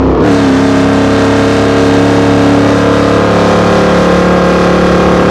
charger2012_gear.wav